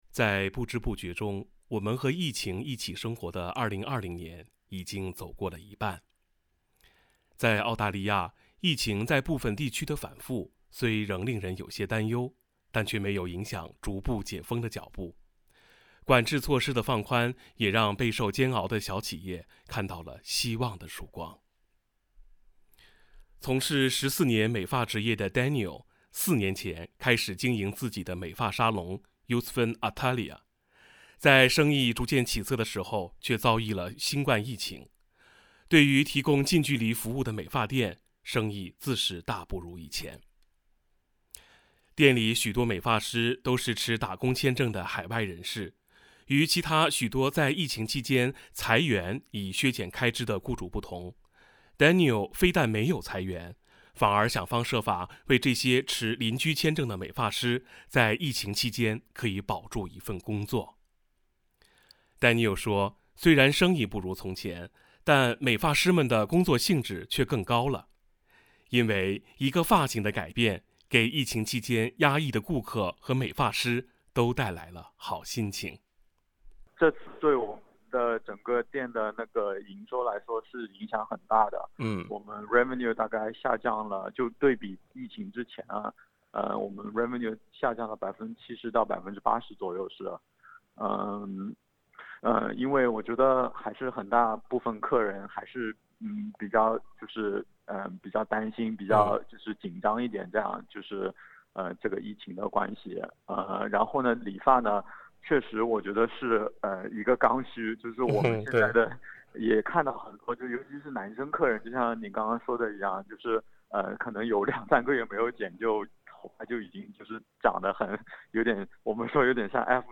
在澳大利亚，疫情在部分地区的反复虽仍令人有些担忧，这却没有影响逐渐解封的脚步，管制措施的放宽也让备受煎熬的小企业看到了希望的曙光。（点击上方图片，收听采访录音 ）